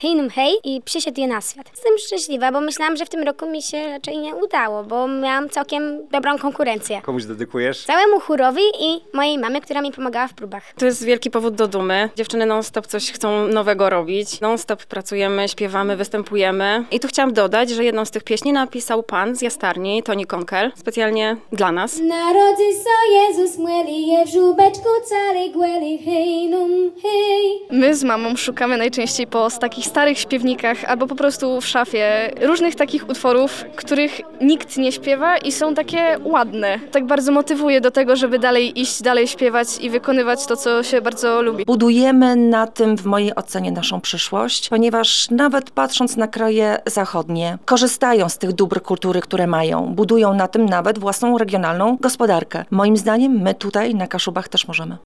Blisko 300 artystów wystąpiło w Pomorskim Konkursie Kaszubskiej Pieśni Bożonarodzeniowej w Szemudzie.